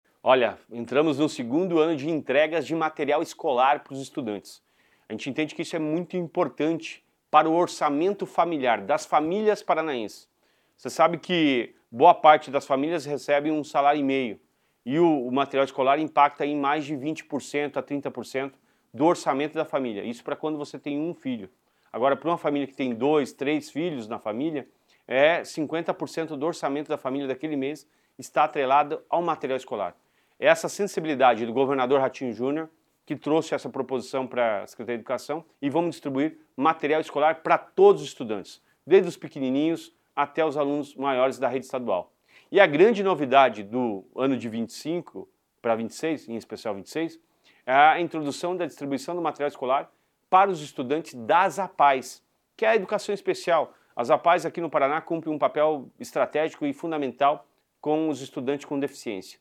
Sonora do secretário Estadual da Educação, Roni Miranda, sobre a entrega de kits para alunos da rede estadual em 2026